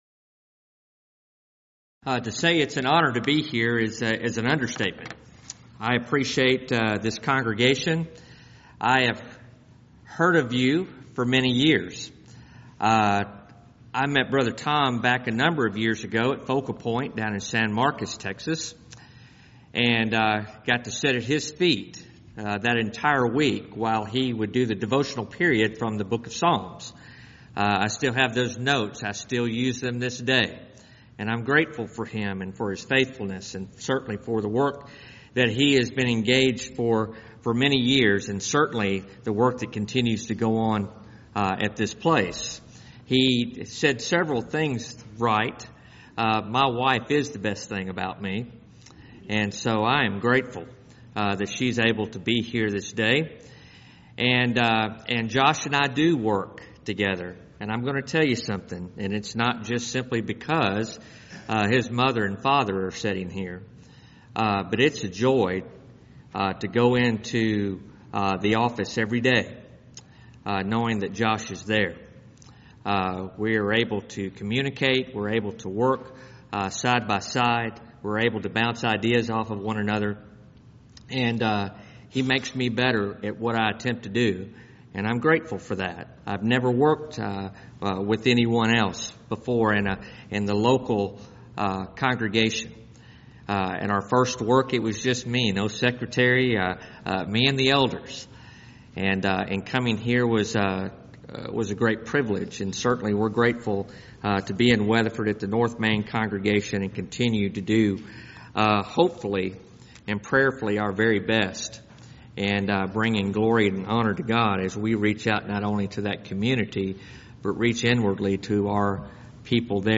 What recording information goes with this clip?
Alternate File Link File Details: Series: Back to the Bible Lectures Event: 8th Annual Back To The Bible Lectures Theme/Title: Do You Know The Christ?